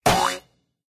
wood_hit_02.ogg